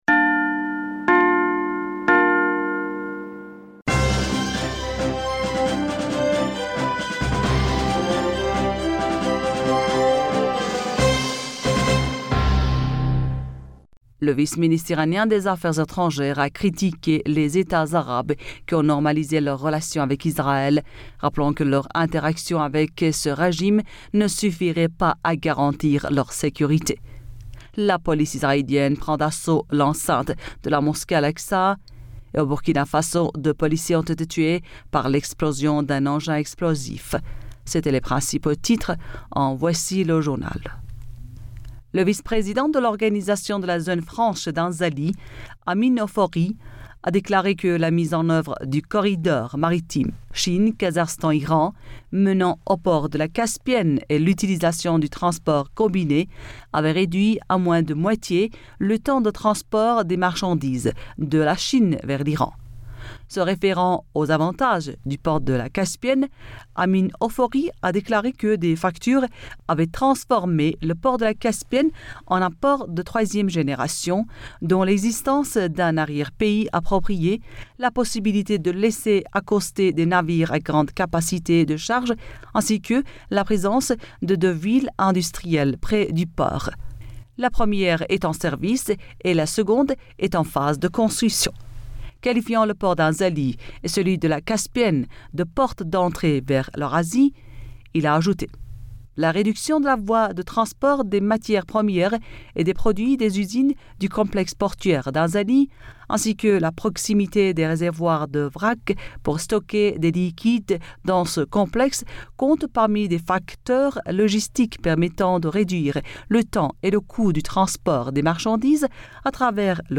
Bulletin d'information Du 18 Avril 2022